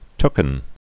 (tkən) Chiefly Southern US